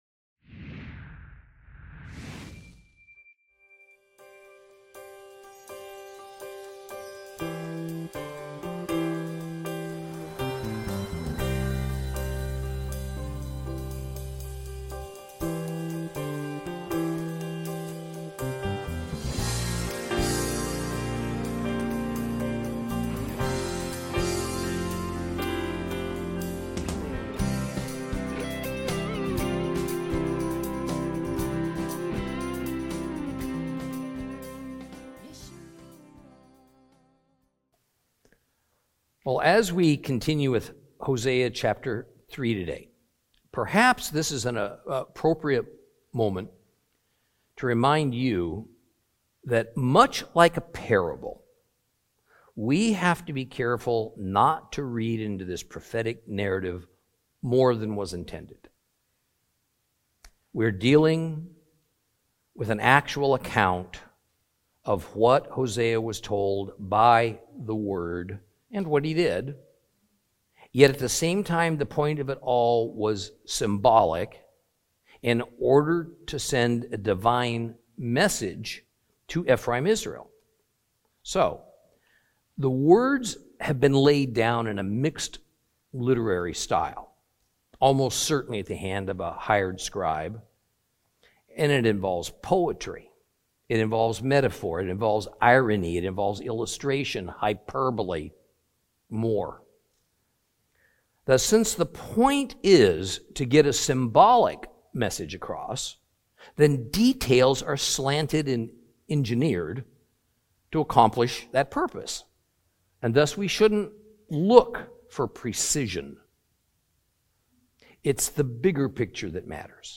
Teaching from the book of Hosea, Lesson 7 Chapters 3 and 4.